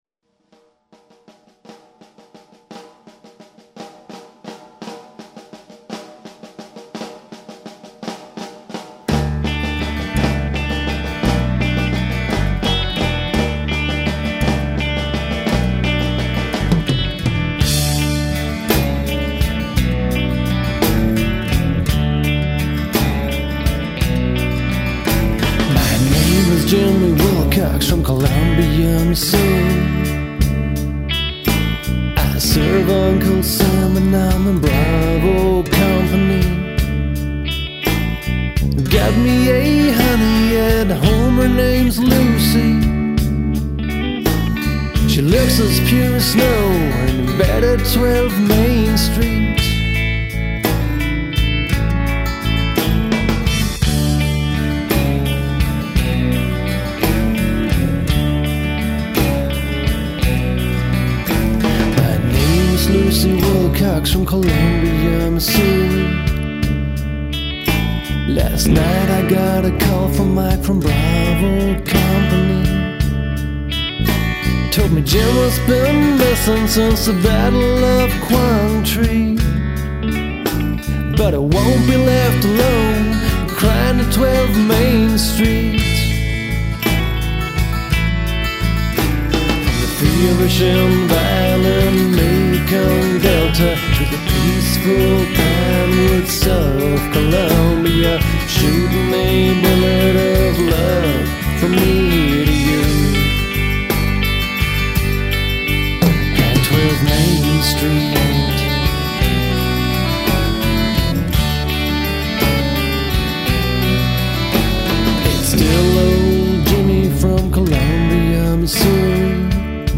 a whole bunch of mesmerizing alternative country songs